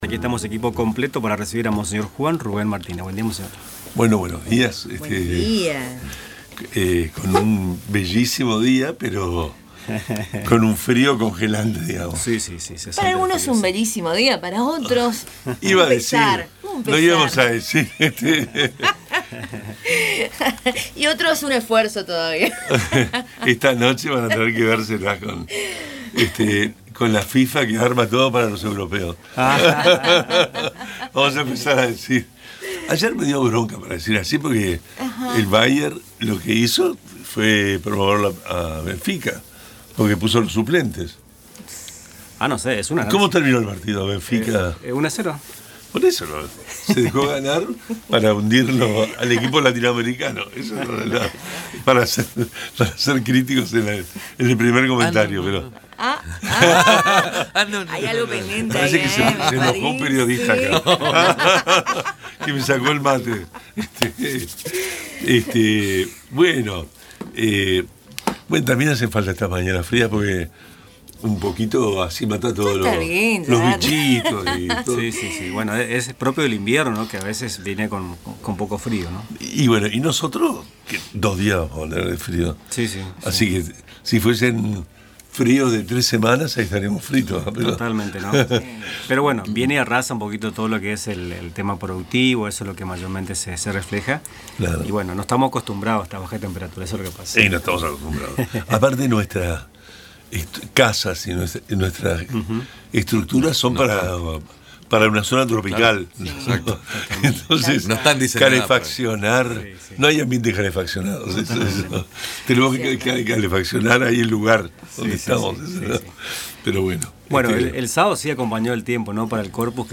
En su habitual participación en Radio Tupambaé, el obispo de Posadas, monseñor Juan Rubén Martínez, abordó con firmeza la crisis social que atraviesa el país. Advirtió sobre la expansión del narcotráfico ante la ausencia del Estado, denunció la corrupción, el nepotismo y la complicidad de sectores del poder, y llamó al compromiso cristiano frente a la desigualdad y la desesperanza. También reflexionó sobre el impacto del frío en viviendas precarias y celebró la masiva participación en el Corpus Christi.